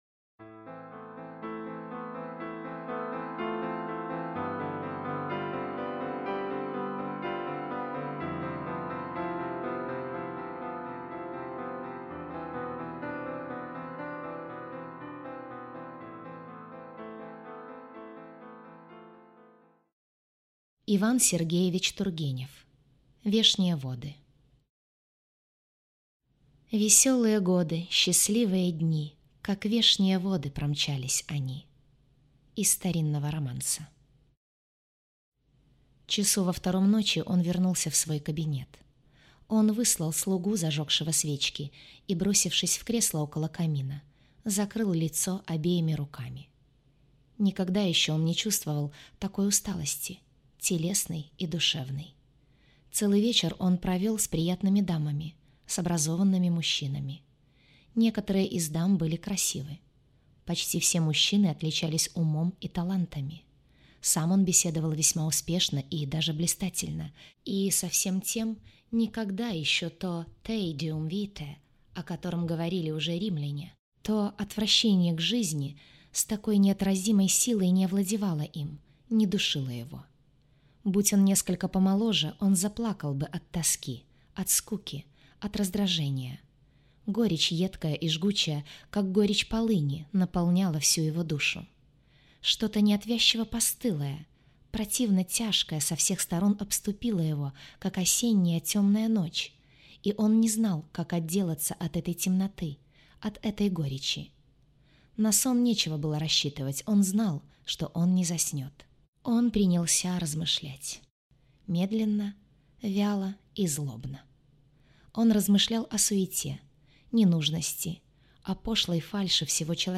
Aудиокнига Вешние воды